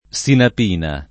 DOP: Dizionario di Ortografia e Pronunzia della lingua italiana
[ S inap & na ]